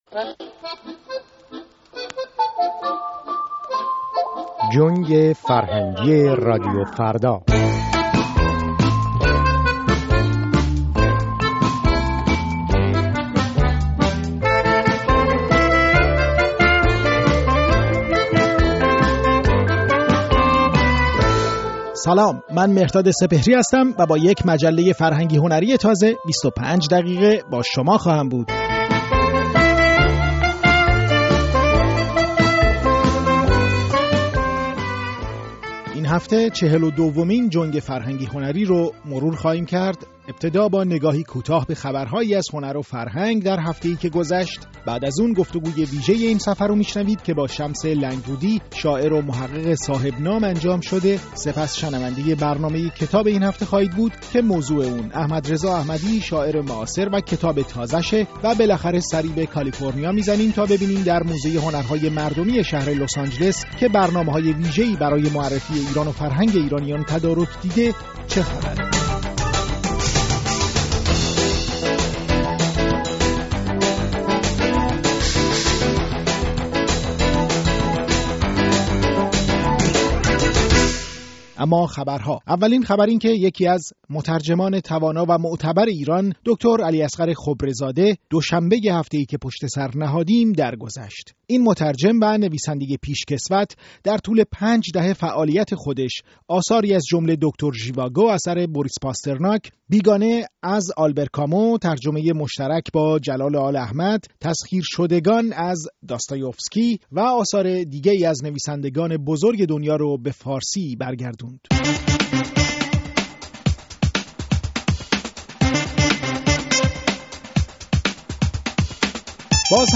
گفت‌وگو با محمد شمس لنگرودی در جنگ فرهنگی